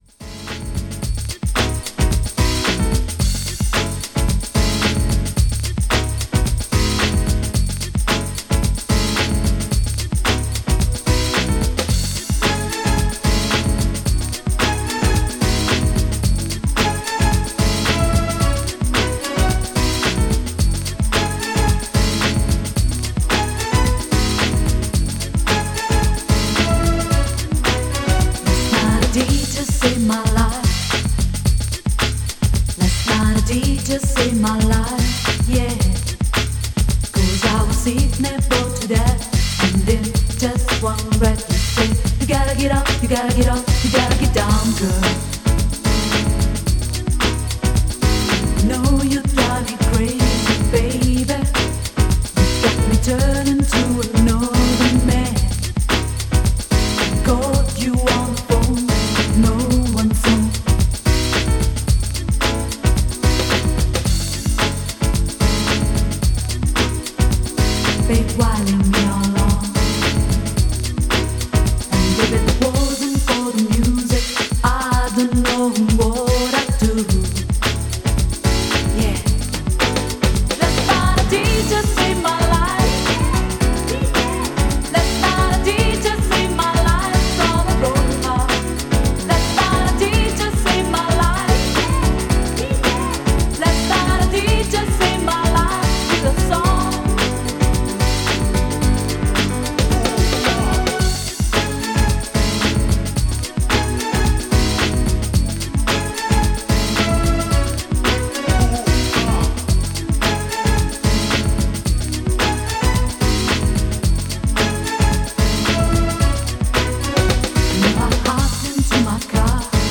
STYLE House